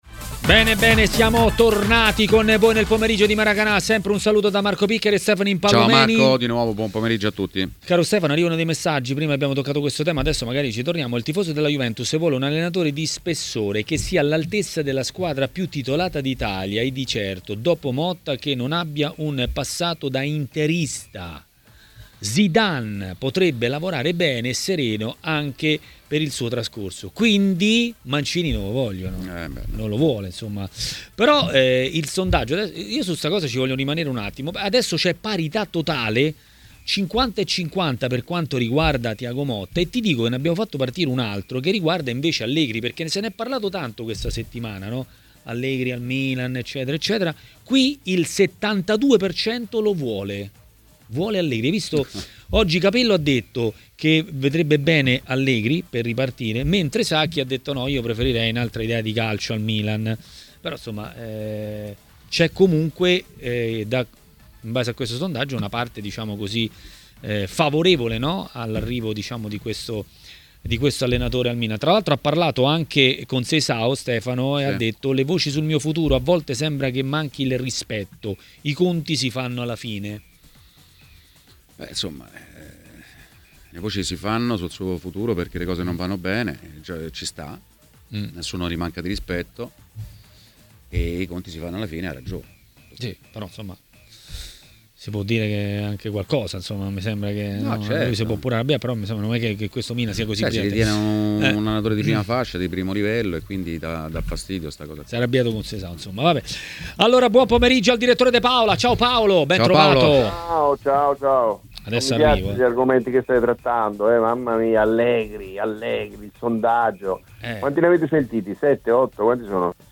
L'ex calciatore Massimo Orlando è stato ospite di Maracanà, trasmissione di TMW Radio.